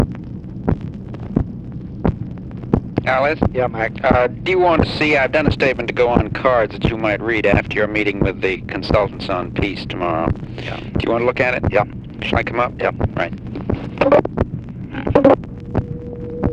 Conversation with MCGEORGE BUNDY, October 20, 1964
Secret White House Tapes